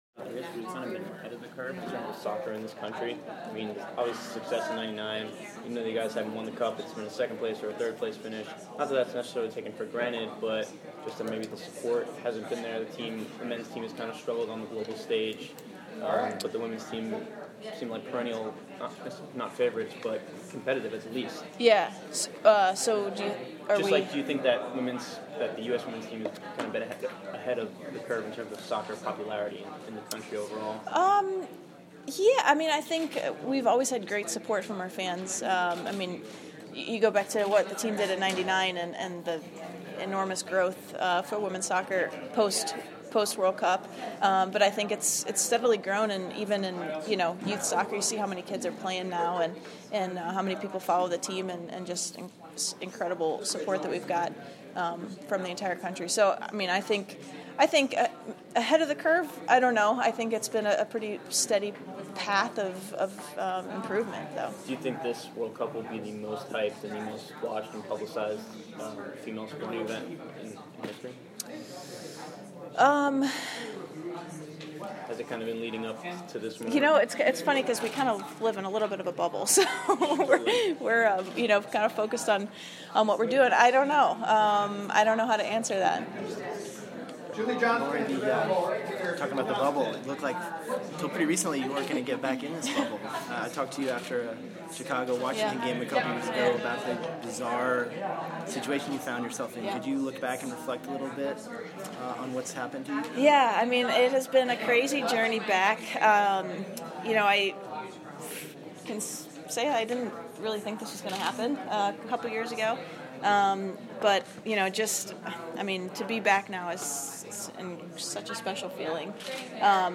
Lori Chalupny roundtable